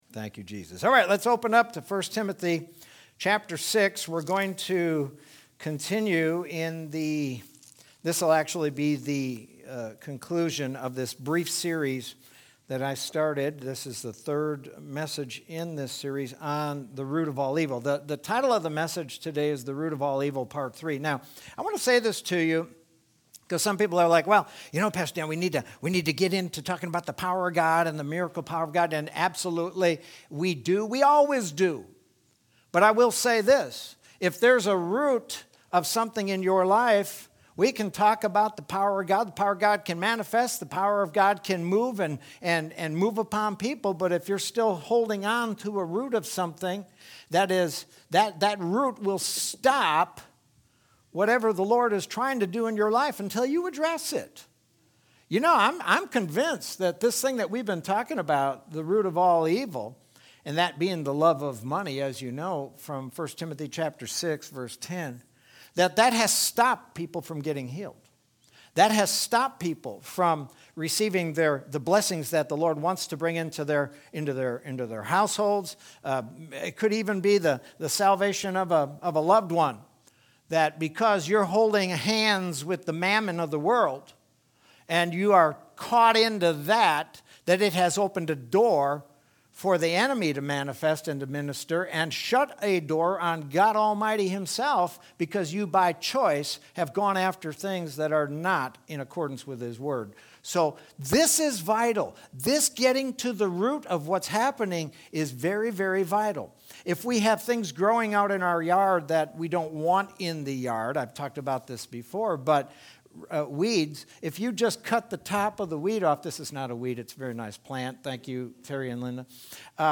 Sermon from Sunday, February 14th, 2021.